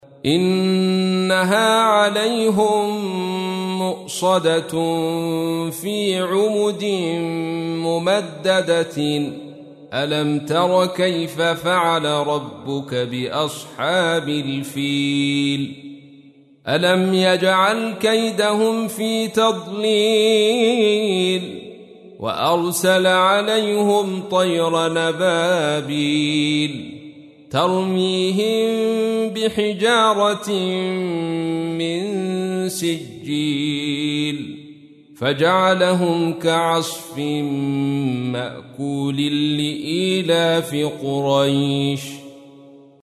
تحميل : 105. سورة الفيل / القارئ عبد الرشيد صوفي / القرآن الكريم / موقع يا حسين